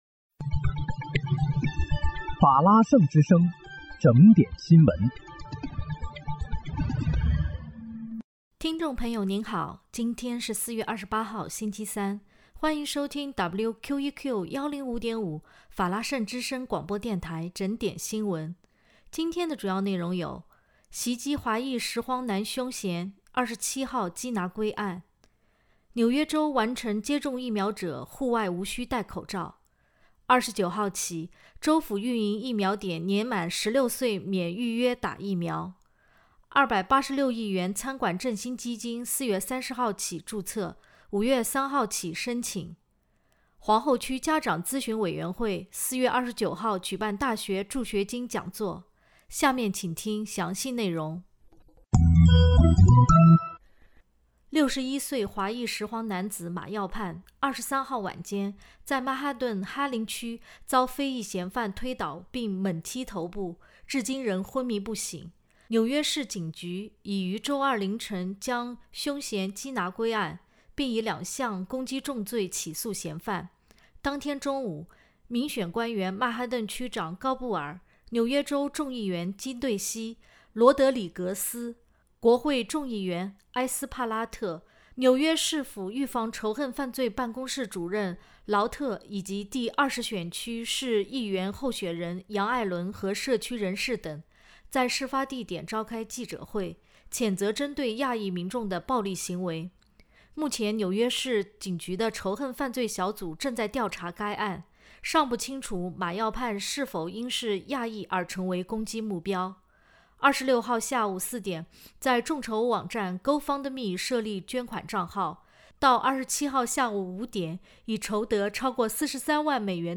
4月28日（星期三）纽约整点新闻